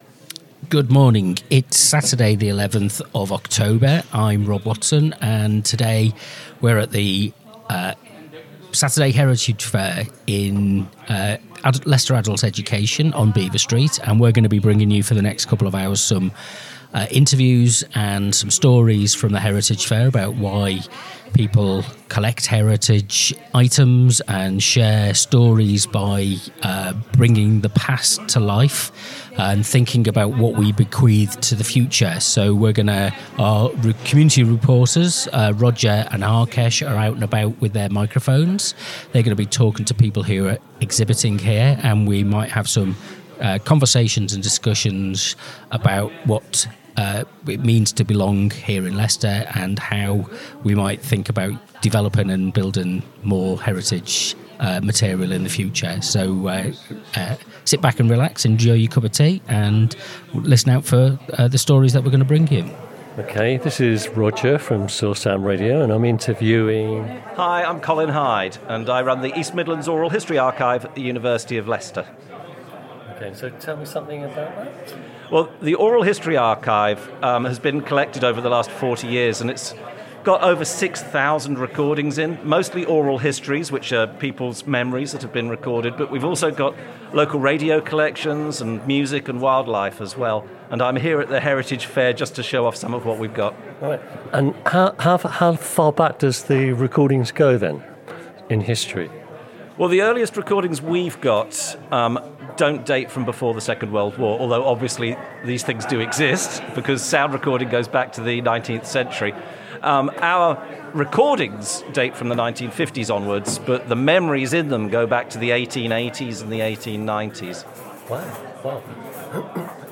Produced by Soar Sound Radio, the event brought together a remarkable mix of people – collectors, re-enactors, archivists, and community broadcasters – all united by a passion for discovering how the past continues to shape our sense of belonging in Leicester today.